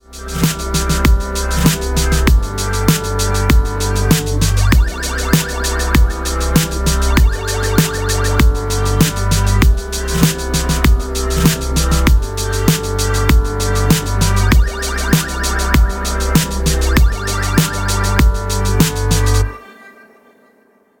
Background Music. Loop. Instrumental Music.